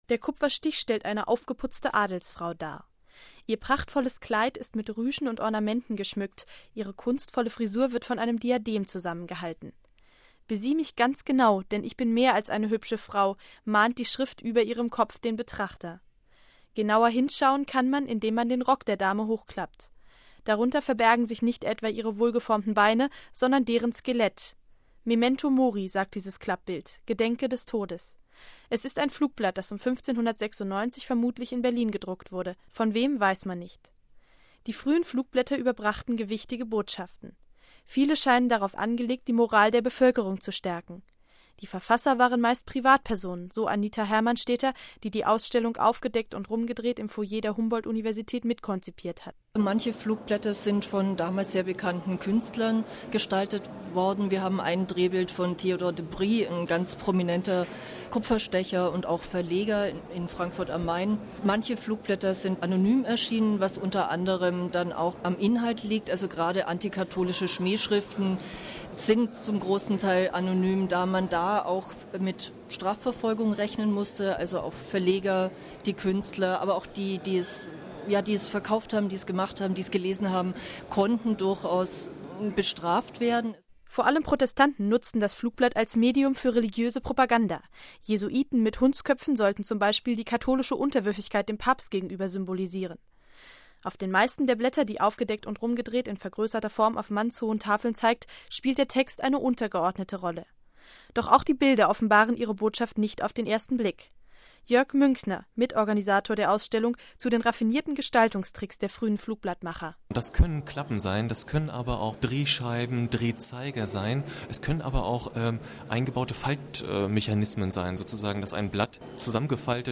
Inforadio rbb, Beitrag vom 25.12.2006; Bericht zur Ausstellung: Aufgedeckt und rumgedreht